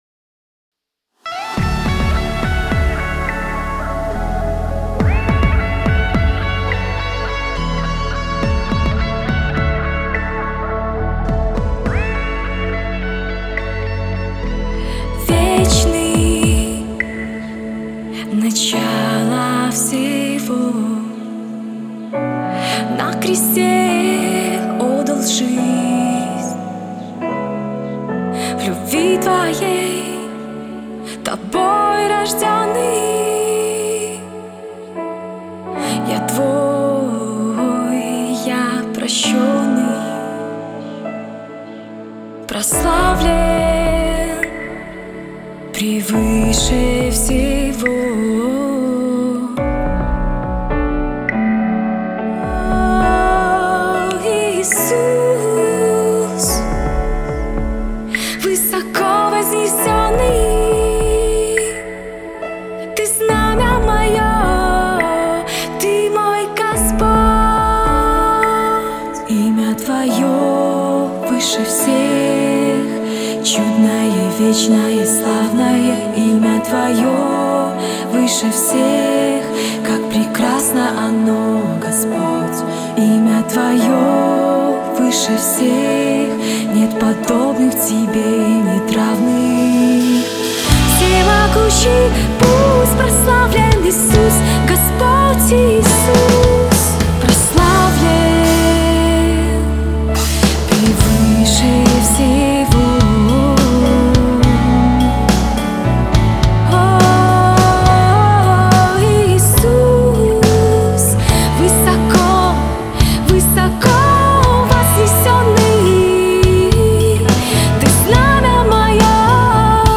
457 просмотров 413 прослушиваний 65 скачиваний BPM: 105